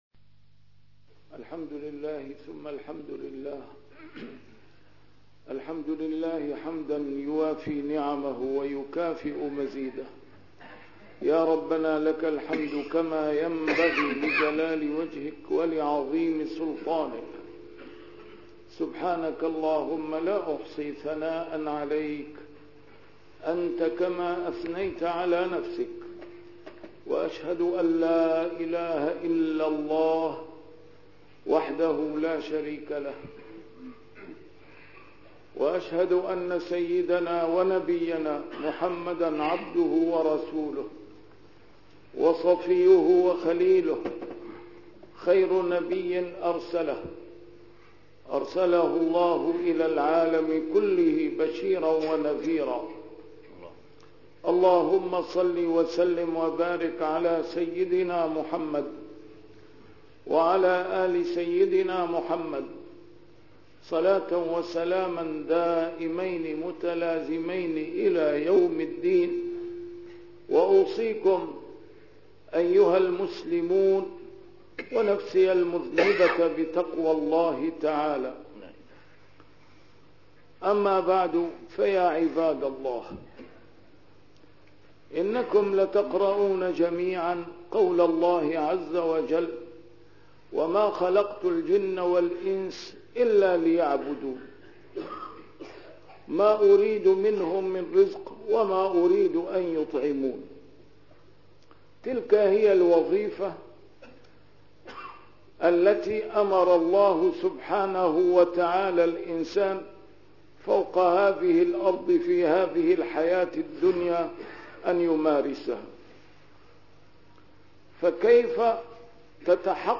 A MARTYR SCHOLAR: IMAM MUHAMMAD SAEED RAMADAN AL-BOUTI - الخطب - الصبر والشكر